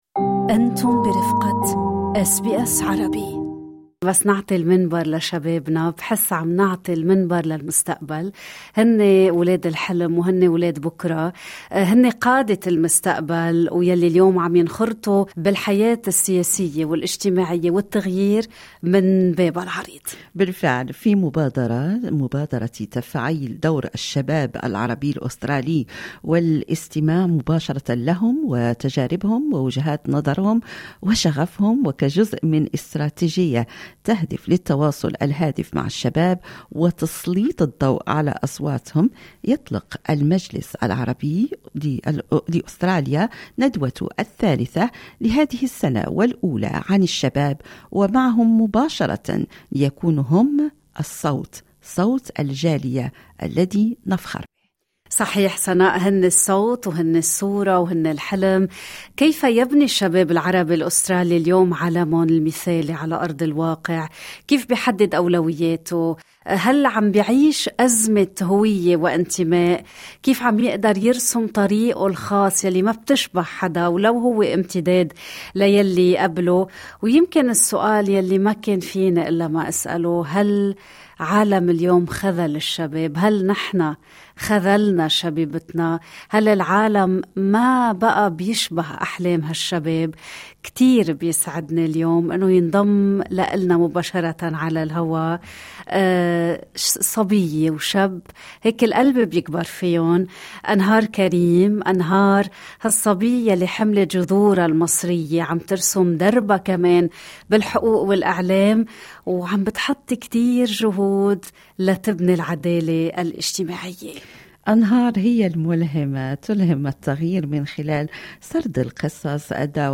share their view as young Arab Australians in creating their ideal world inspiring young leaders driving change in our community on SBS Arabic “Good Morning Australia”.